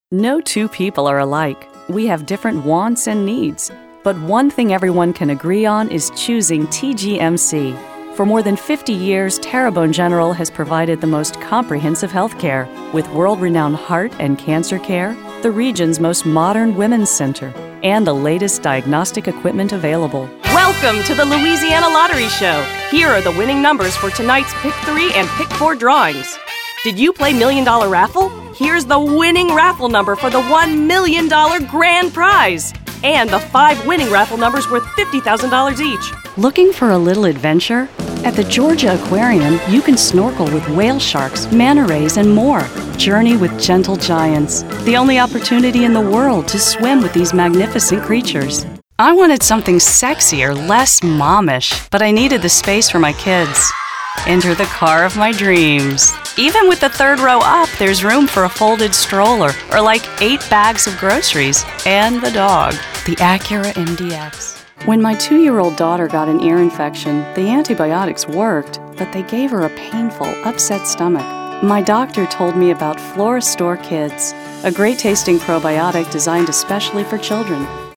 Voiceover
Commercial Demo